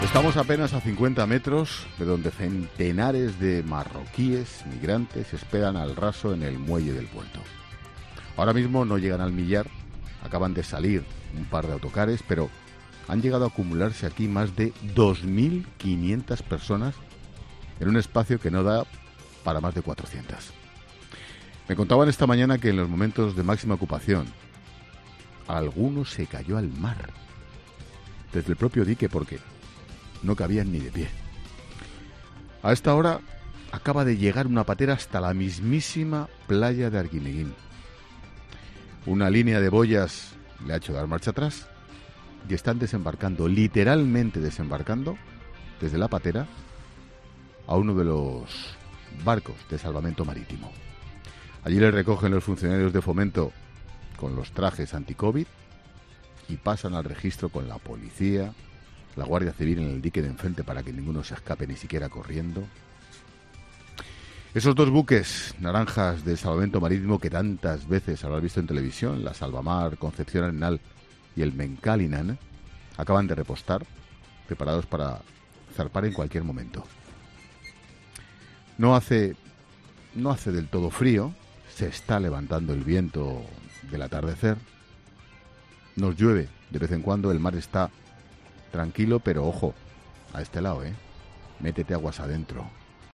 AUDIO: La llegada de una patera sorprende a Ángel Expósito en directo desde Arguineguín